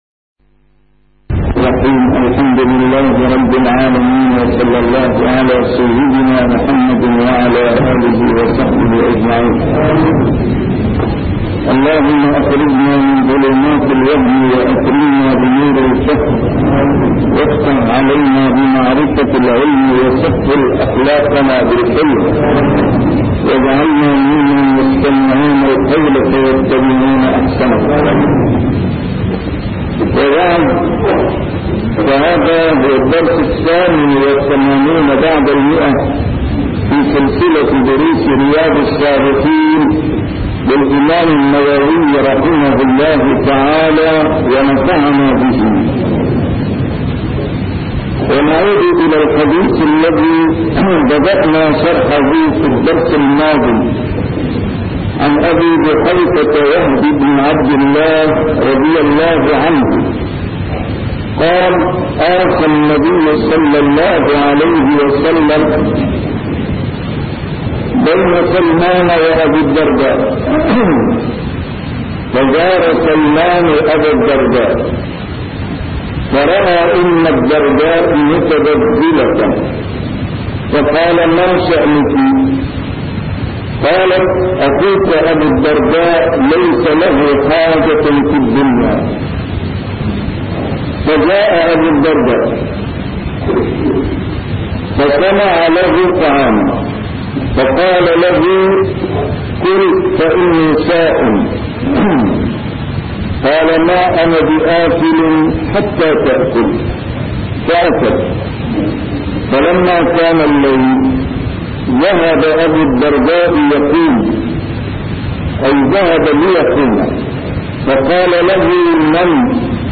A MARTYR SCHOLAR: IMAM MUHAMMAD SAEED RAMADAN AL-BOUTI - الدروس العلمية - شرح كتاب رياض الصالحين - 188- شرح رياض الصالحين: الاقتصاد في العبادة